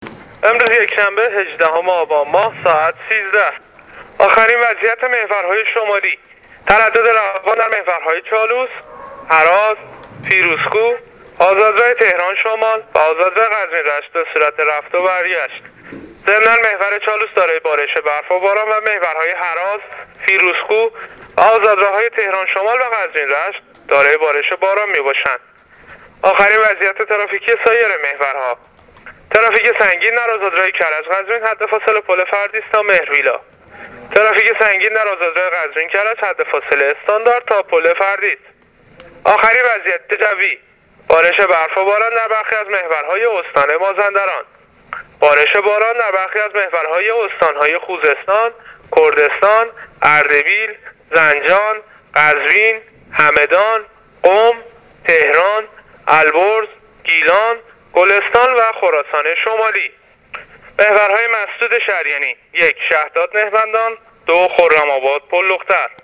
گزارش رادیو اینترنتی از وضعیت ترافیکی جاده‌ها تا ساعت ۱۳، هجدهم آبان